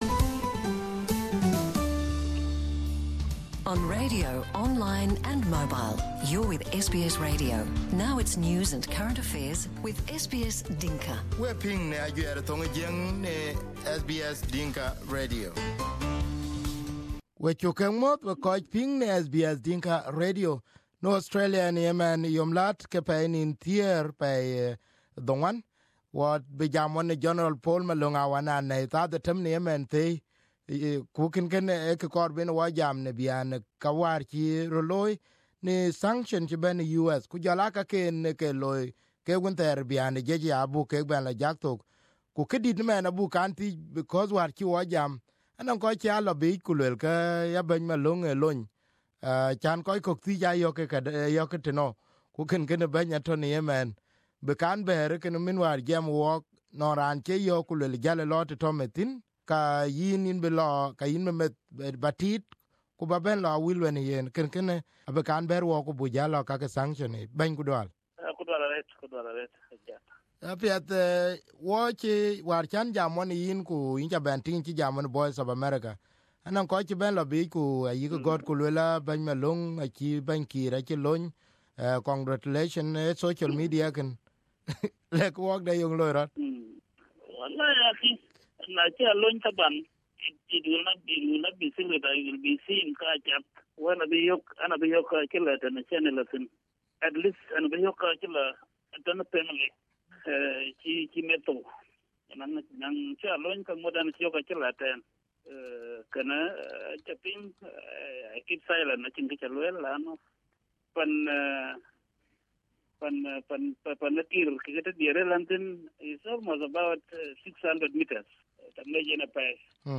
This time not about the death of his daughter but an exclusive interview with how he was running the military and his role during the war and the political crisis.